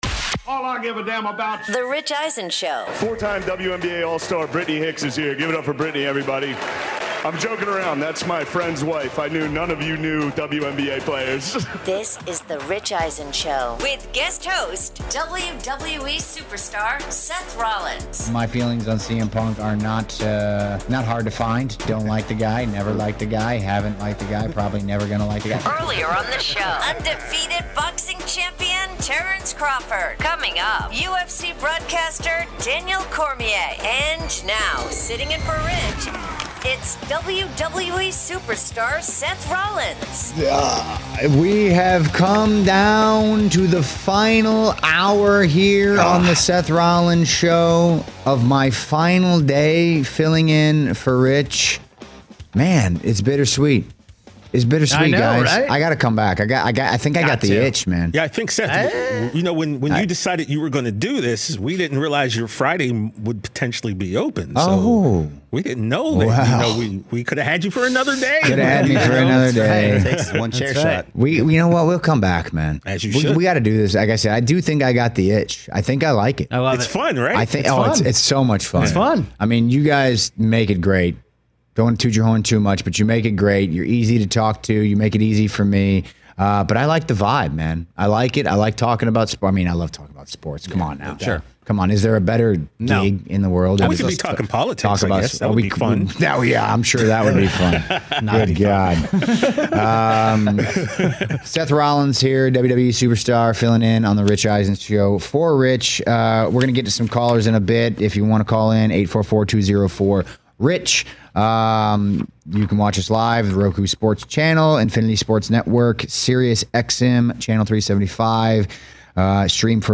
7/17/25 - Hour 3 Guest host WWE Superstar Seth Rollins and the guys react to the surprising retirement of Chargers WR Mike Williams at the age of 30, and Seth power ranks his all-time Top 10 pro wrestling finishing moves.